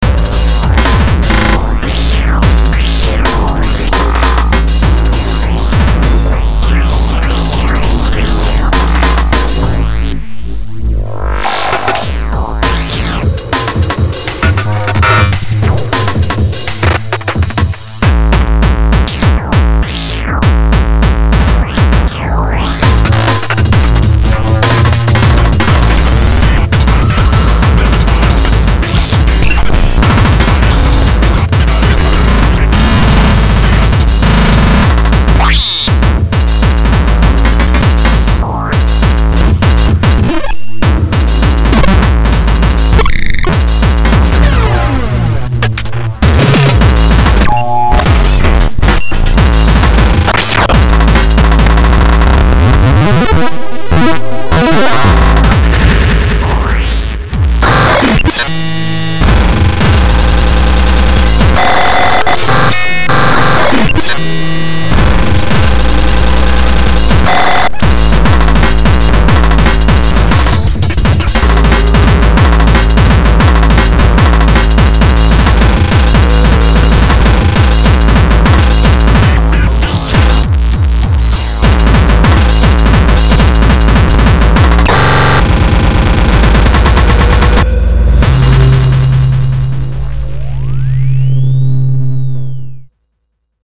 2007 May Nu-Jazz from Winnipeg...